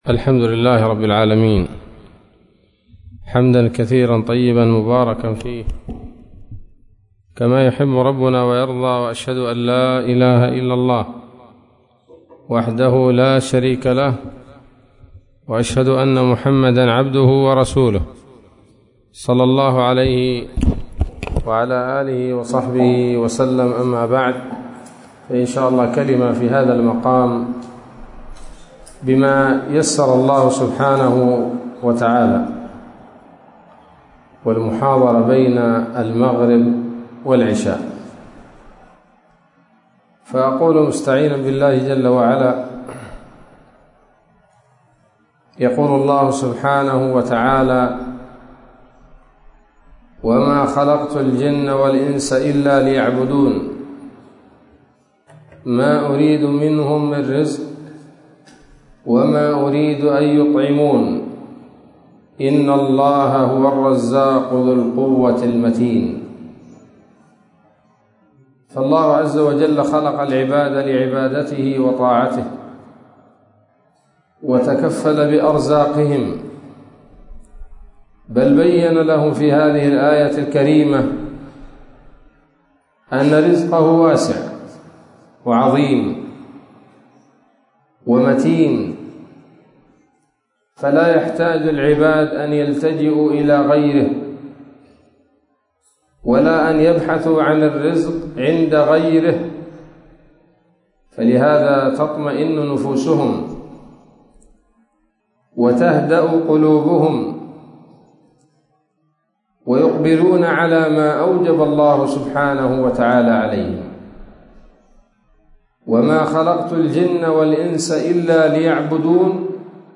كلمة قيمة بعنوان: (( ‌العمر فرصة لا تضيعها )) عصر الخميس 27 ربيع الآخر 1443هـ، مسجد الإمام الوادعي